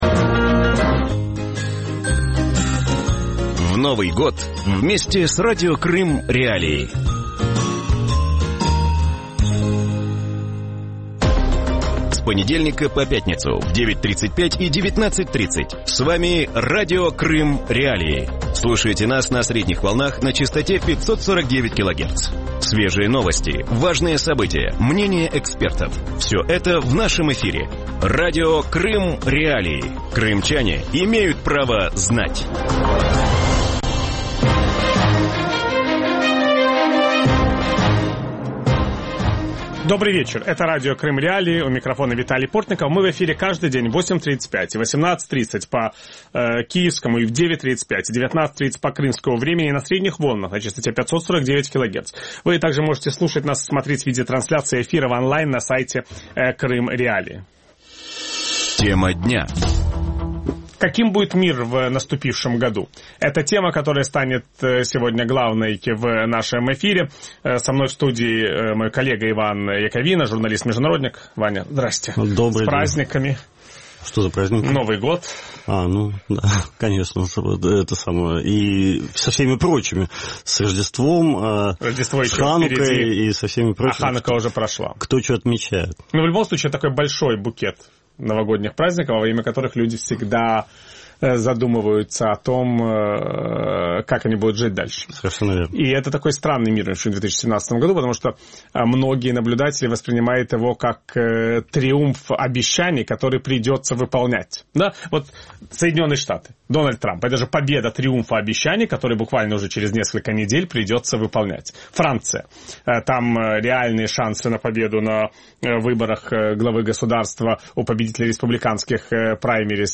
У вечірньому ефірі Радіо Крим.Реалії говорять про те, що чекає світ у 2017 році. Яким буде зовнішньополітичний курс нового президента США, що буде означати перемога євроскептиків у Франції для Євросоюзу, чи варто очікувати ослаблення режиму санкцій і перемогу над ІДІЛ у Сирії та Іраку?
Ведучий: Віталій Портников.